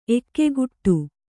♪ ekkeguṭṭu